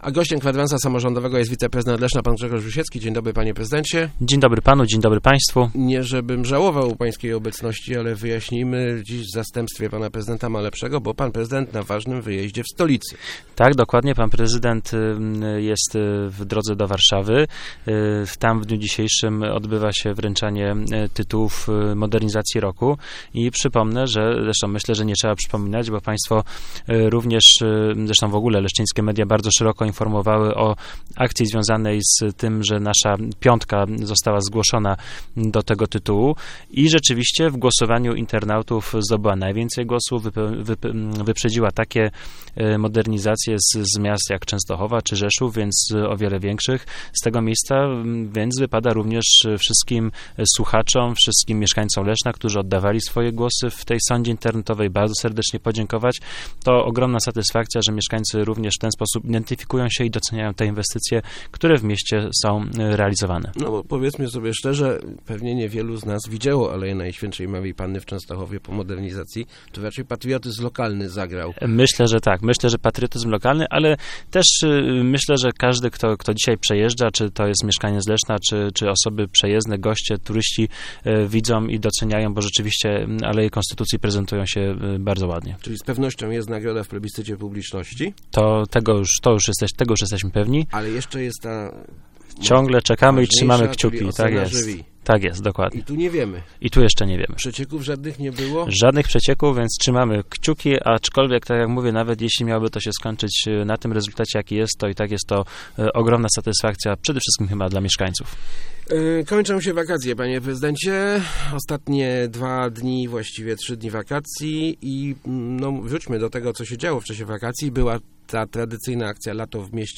Gościem Kwadransa był wiceprezydent Grzegorz Rusiecki.